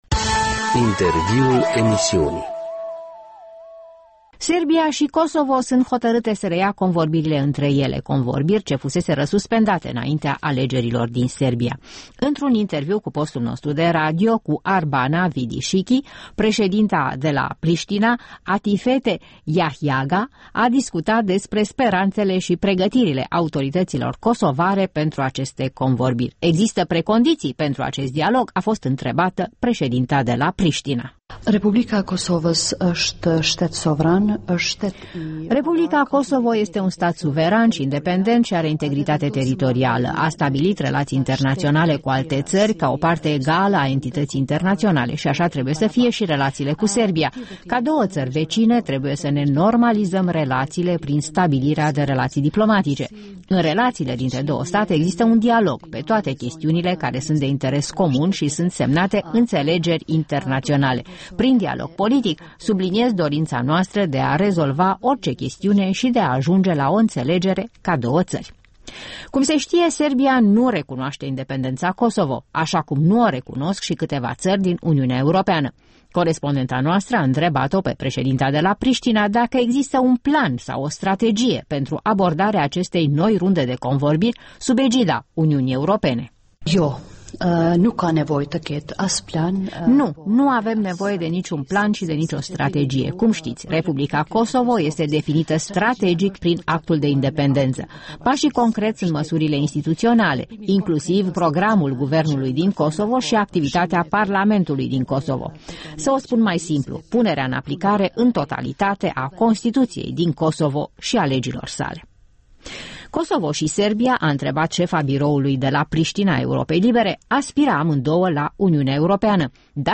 Un interviu cu președinta Republicii Kosovo.